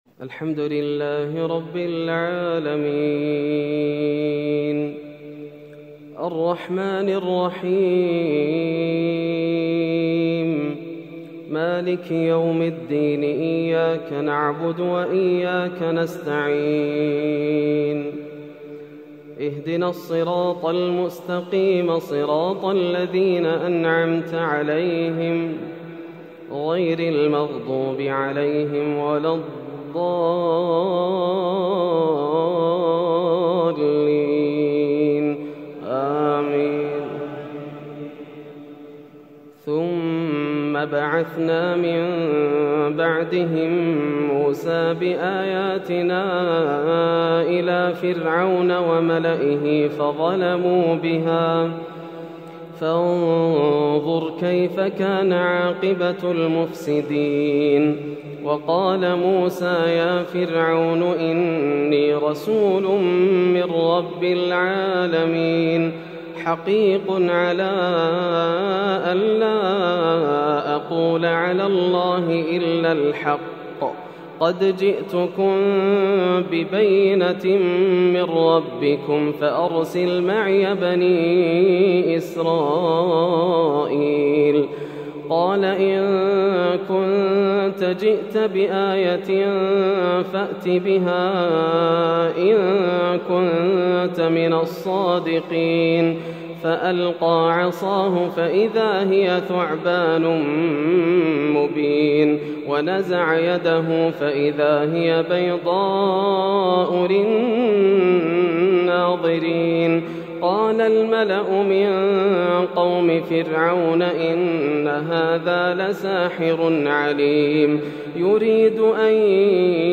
صلاة التراويح من سورتي الأعراف والأنفال للشيخ ياسر الدوسري | ليلة ١٣ رمضان ١٤٣١هـ > رمضان 1431هـ > مزامير الفرقان > المزيد - تلاوات الحرمين